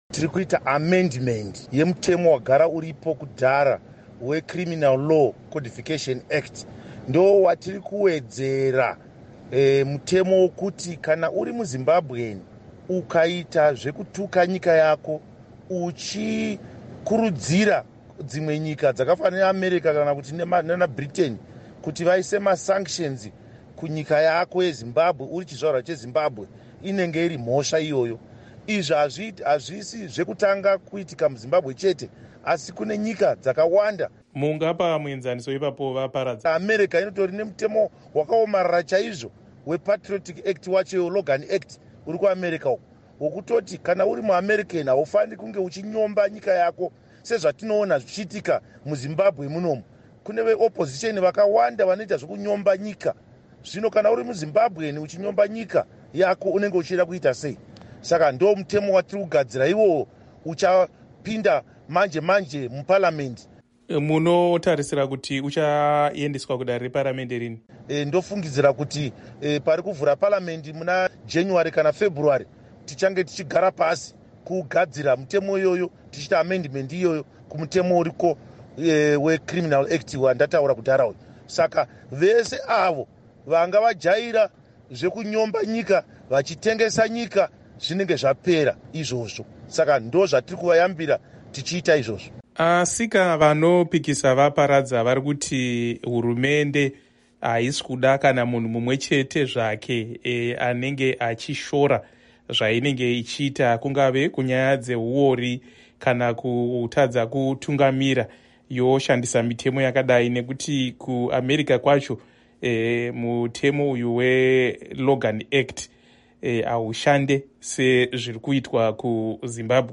Hurukuro naVaKindness Paradza